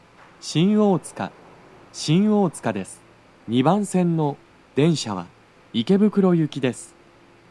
スピーカー種類 BOSE天井型
足元注意喚起放送の付帯は無く、フルの難易度は普通です
到着放送1